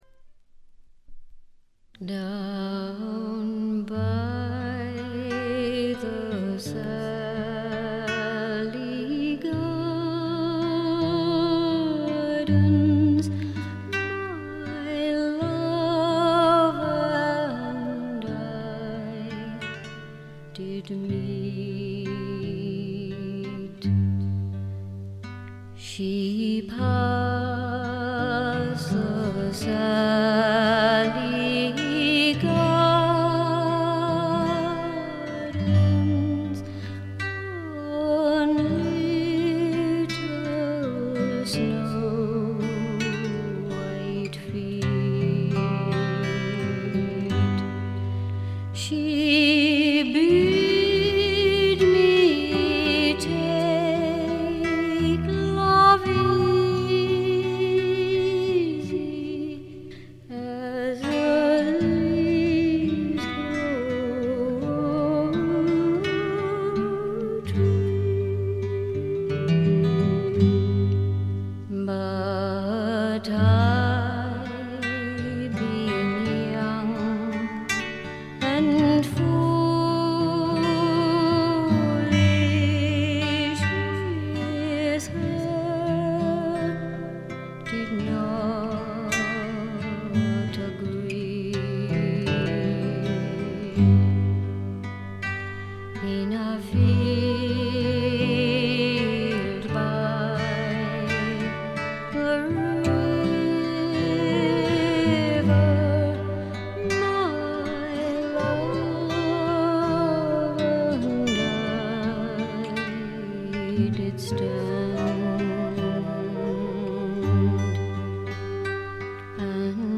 メランコリックな曲が多く彼女のヴォーカルは情感を巧みにコントロールする実に素晴らしいもの。
試聴曲は現品からの取り込み音源です。
Vocals, Harp [Irish]
Recorded & mixed At Hollywood Studios, Rome, April 1983.